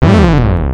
Play, download and share Earthbound partner death original sound button!!!!
earthbound-partner-death.mp3